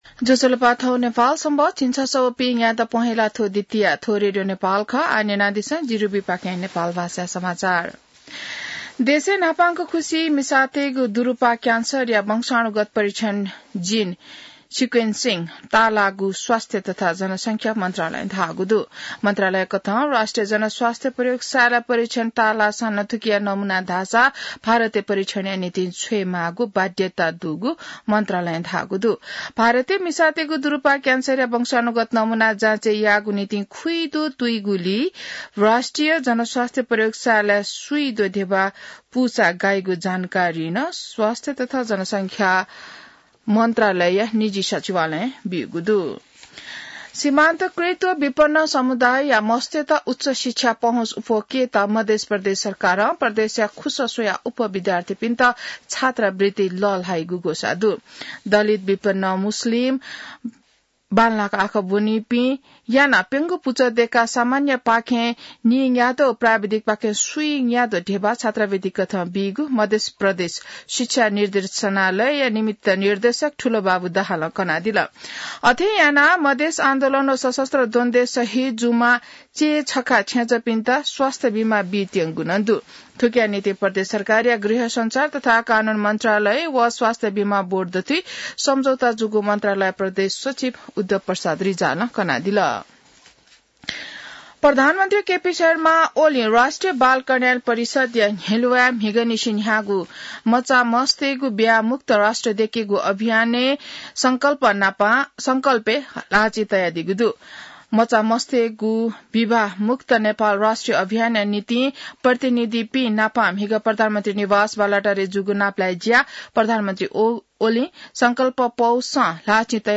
नेपाल भाषामा समाचार : १८ पुष , २०८१